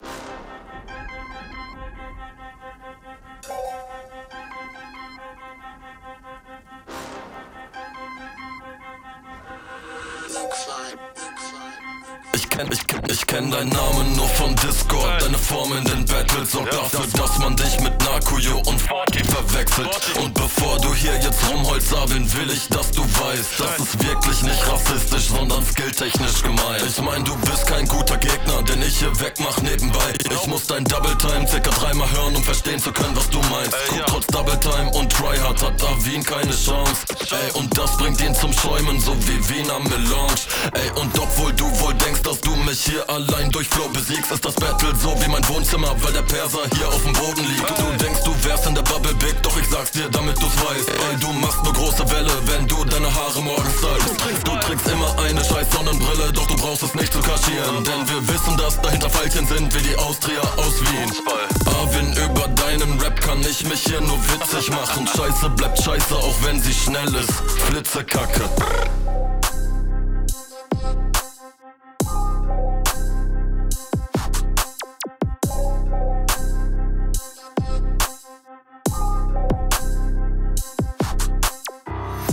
Die Soundqualität hat immer noch dieses dreckige aber wirkt besser als bei der anderen Runde.
Flow: Das scheppert schon bedeutend mehr als die RR.